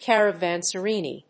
音節car・a・van・se・rai 発音記号・読み方
/k`ærəvˈænsərὰɪ(米国英語)/